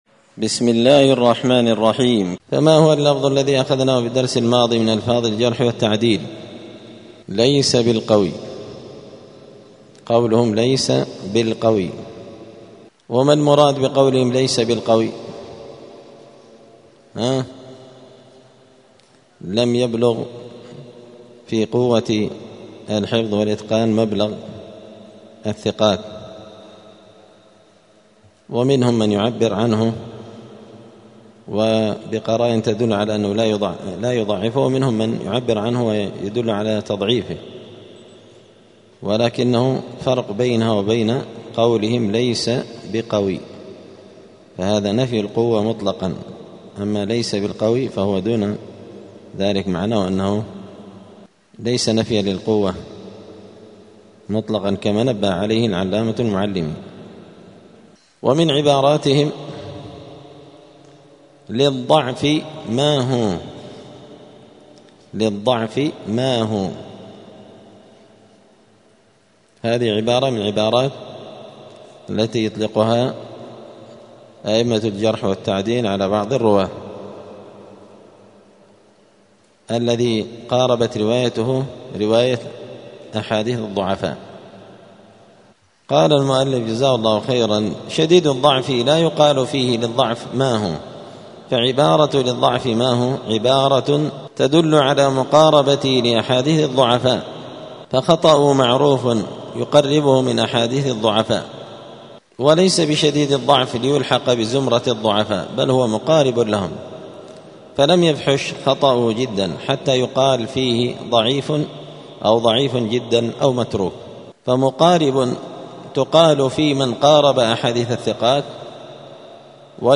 الأربعاء 13 شعبان 1446 هــــ | الدروس، المحرر في الجرح والتعديل، دروس الحديث وعلومه | شارك بتعليقك | 32 المشاهدات